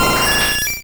Cri de Roucarnage dans Pokémon Rouge et Bleu.